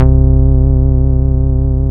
SYN STRANG04.wav